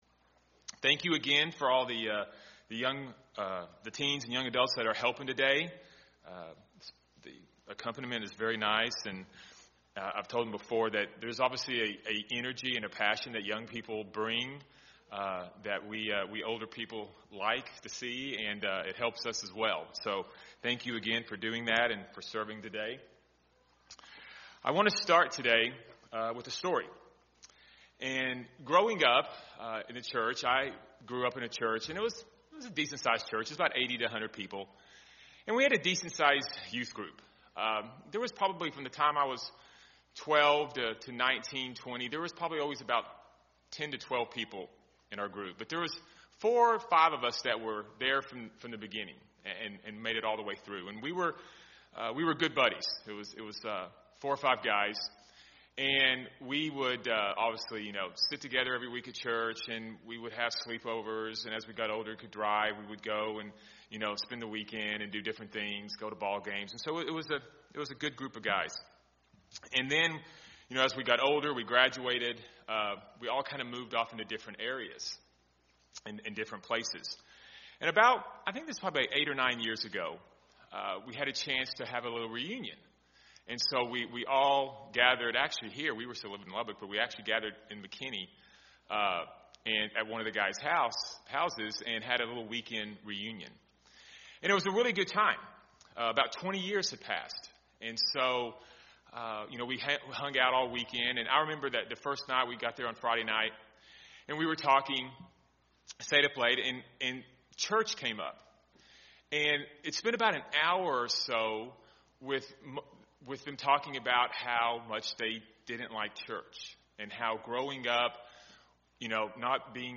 What is the most effective way to respond to someone who wants to leave the church? This sermon will explore some of the most often used reasons/excuses given for leaving church and what our response should be.